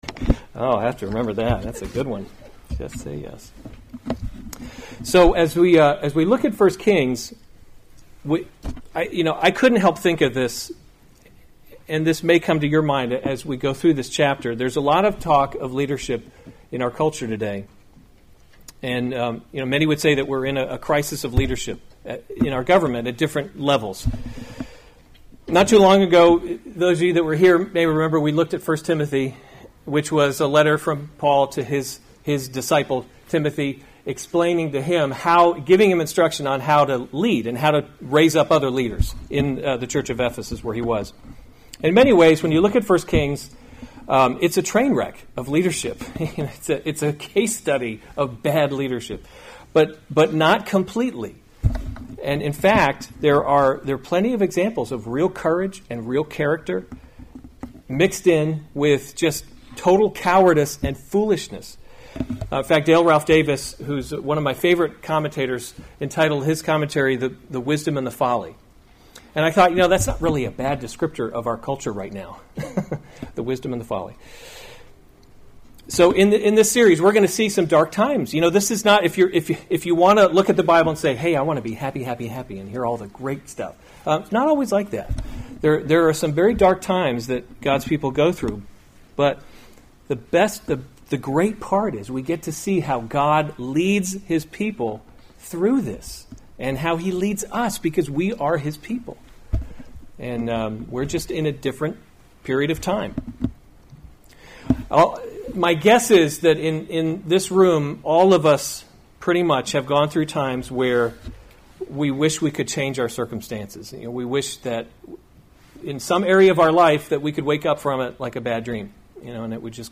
September 8, 2018 1 Kings – Leadership in a Broken World series Weekly Sunday Service Save/Download this sermon 1 Kings 1 Other sermons from 1 Kings David in His Old […]